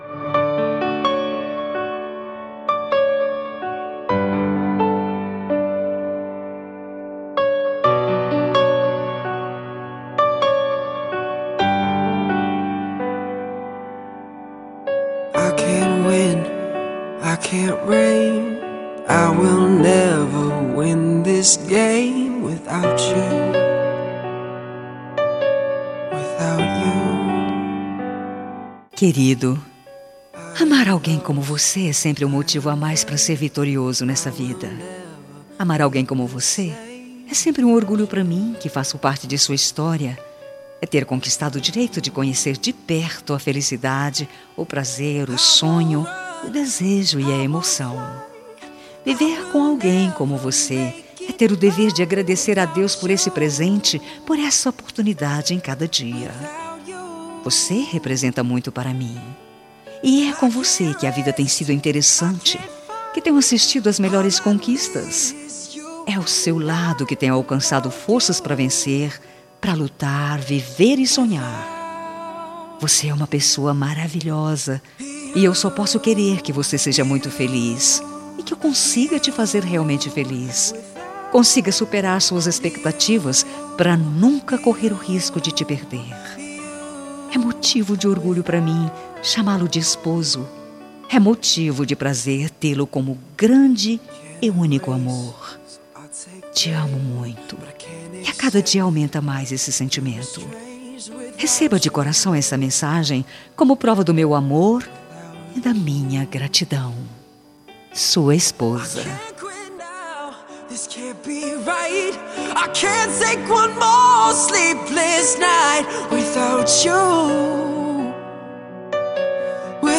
Telemensagem Romântica para Marido – Voz Feminina – Cód: 9068